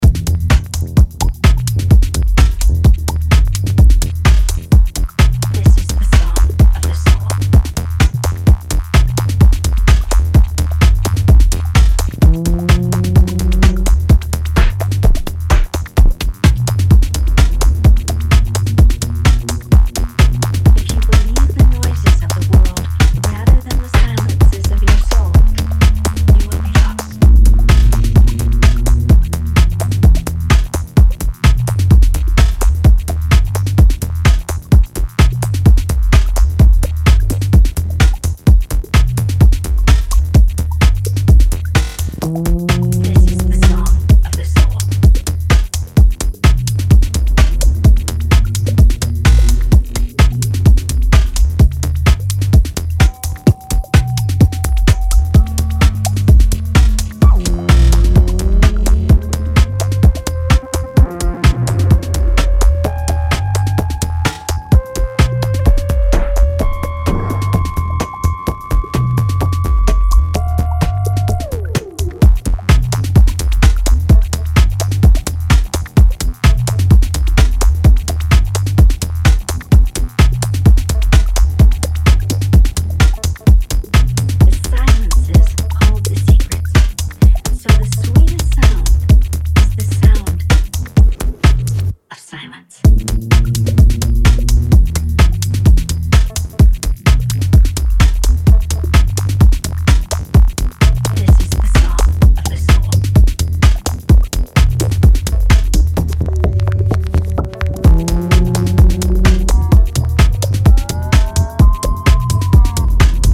2x12inch Vinyl
five finely tuned, detailed groovers